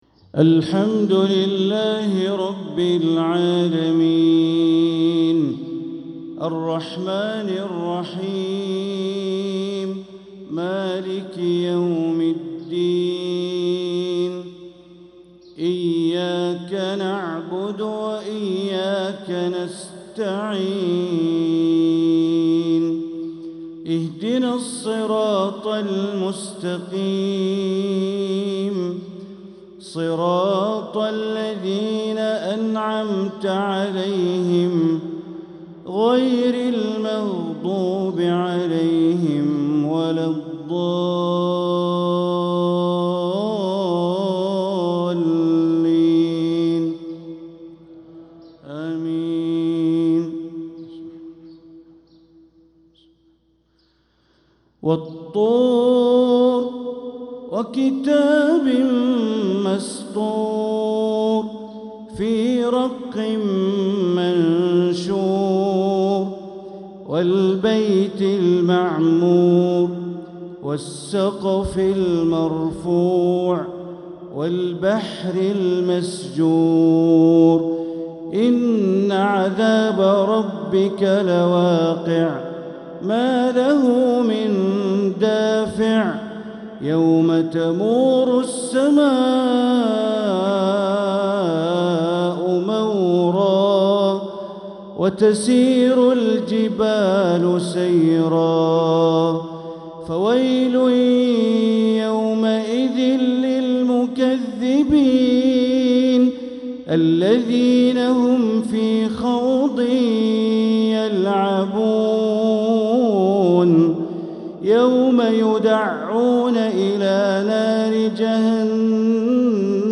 Maghrib prayer from Surat at-Tur 2-2-2025 > 1446 > Prayers - Bandar Baleela Recitations